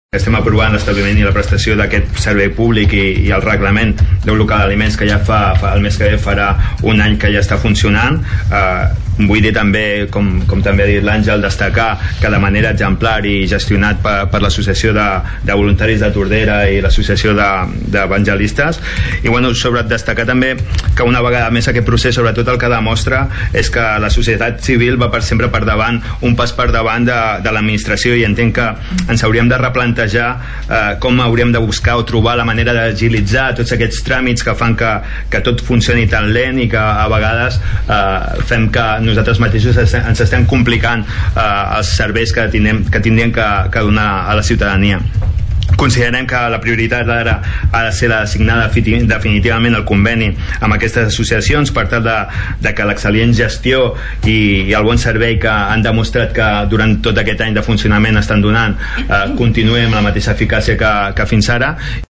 Salva Giralt, regidor de SOM Tordera, recordava que el seu grup havia dedicat molts esforços al projecte del centre “De Tots”. També proposava buscar una fórmula perquè, en casos com aquest, els tràmits administratius siguin més àgils.